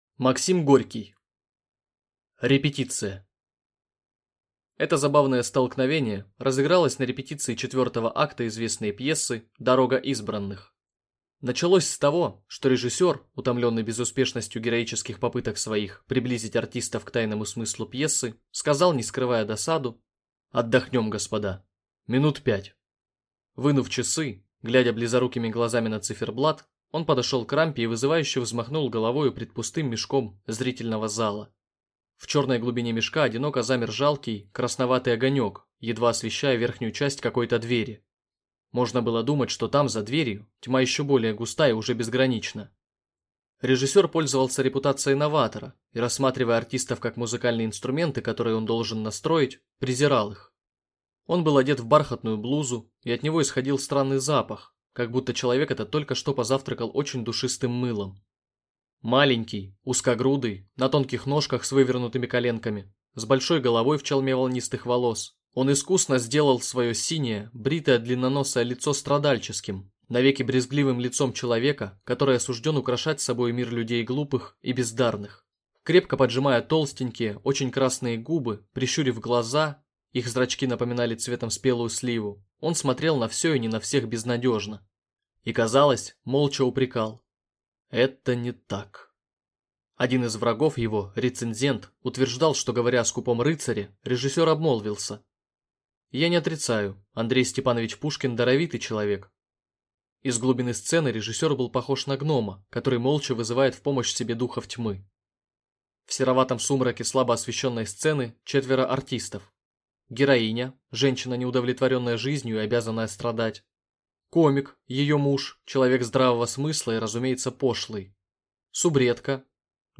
Аудиокнига Репетиция | Библиотека аудиокниг